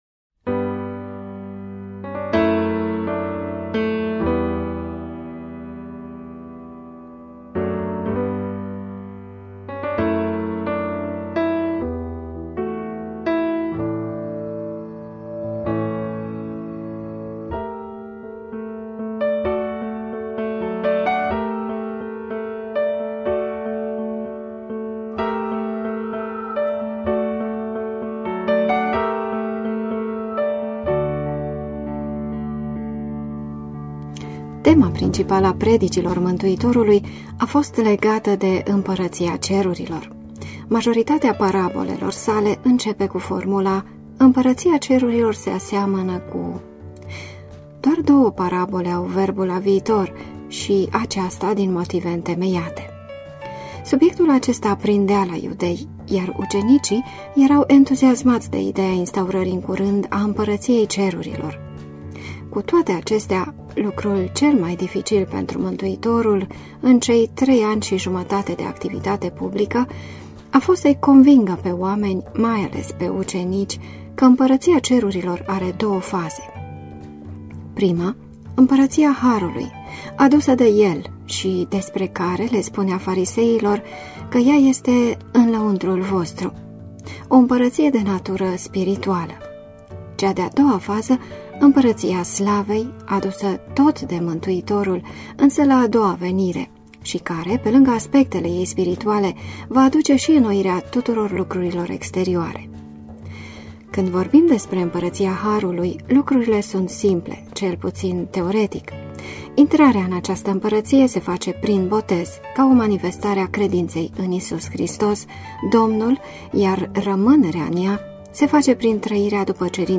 Prelegere în format audio